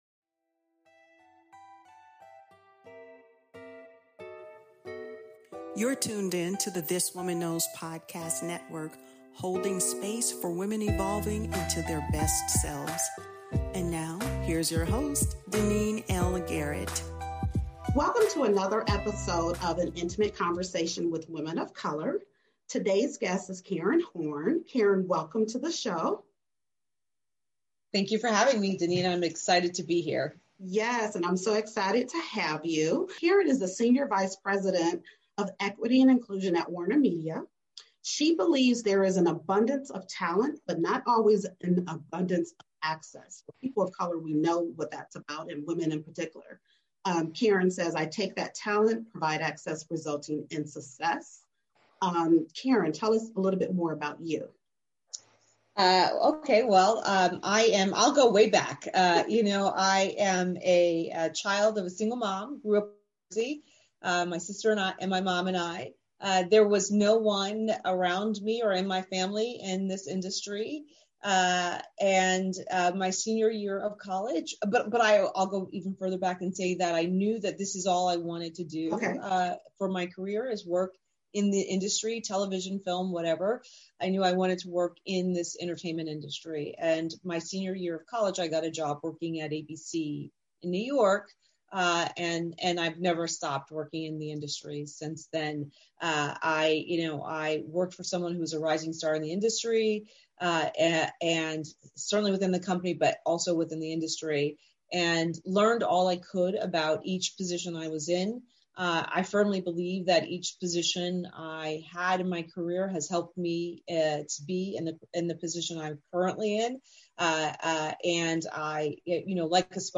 Discovery—for a powerful conversation on what it really takes to thrive in entertainment.